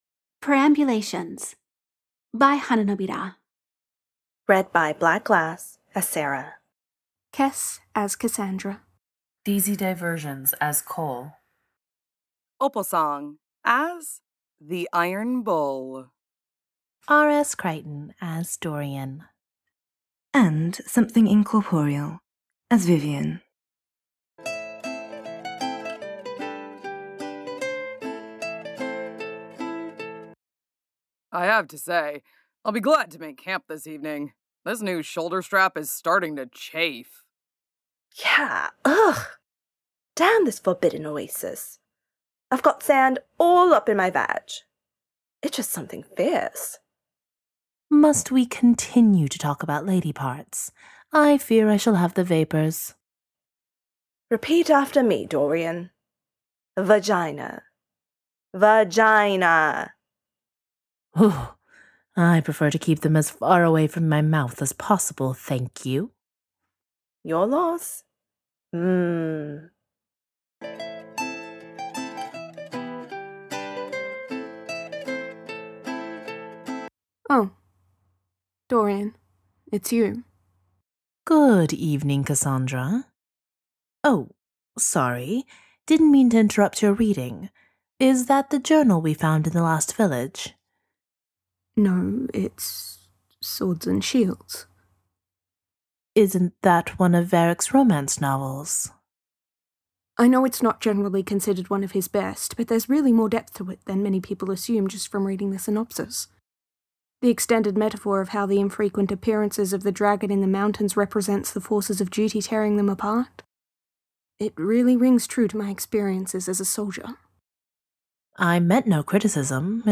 Song/Sound Effects: "Sera Would Never (Instrumental Version)" from the DAI soundtrack